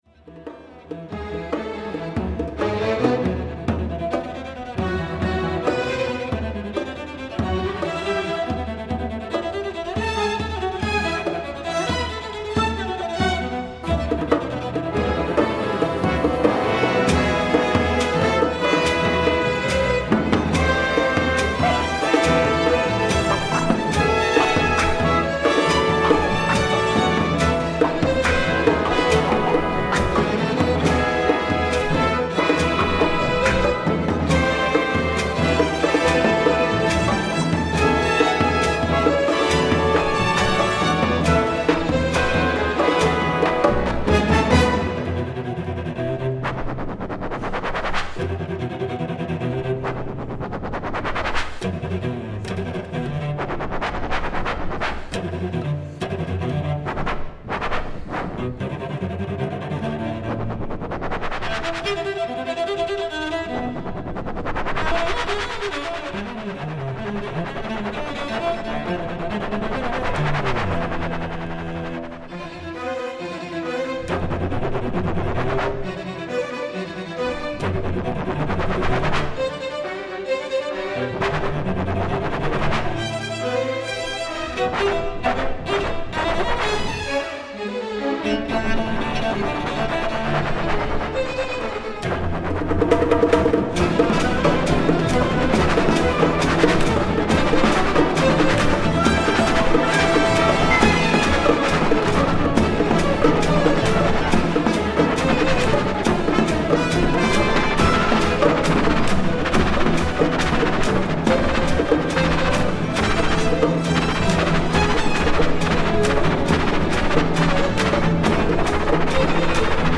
turntables
‘cello